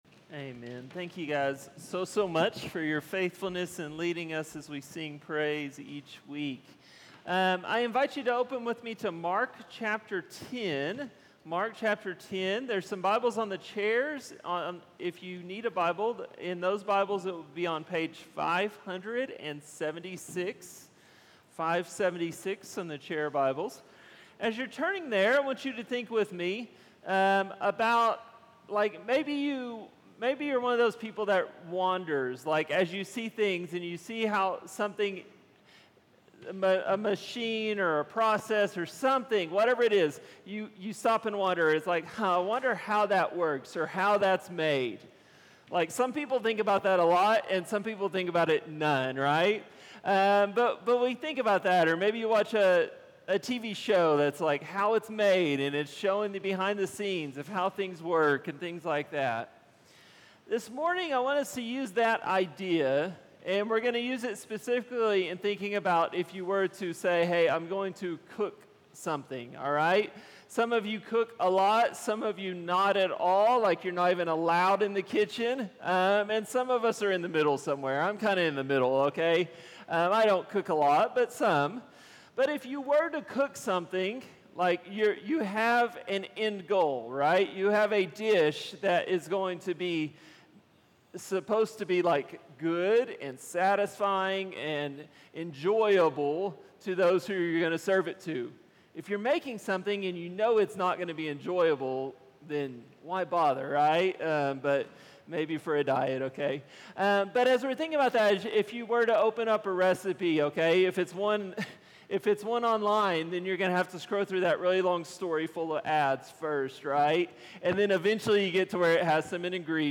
Sermons - HopeValley Church // West Jordan, UT